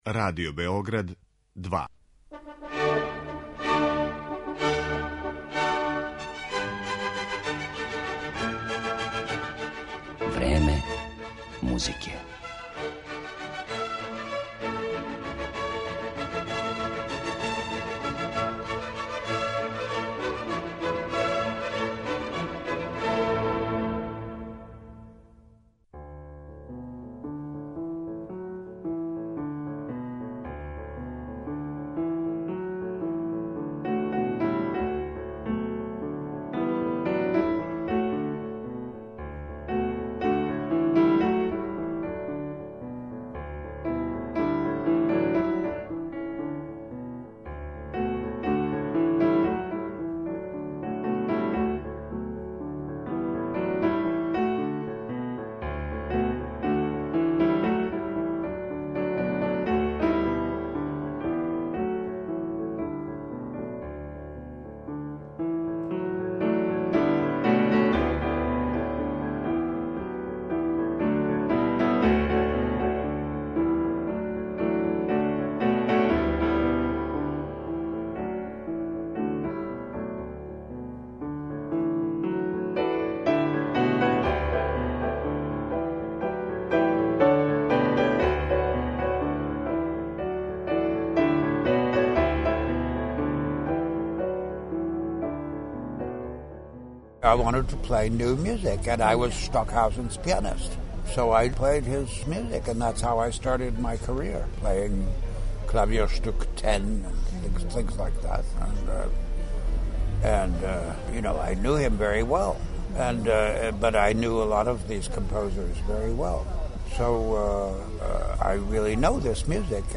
Разговор са Фредериком Ржевским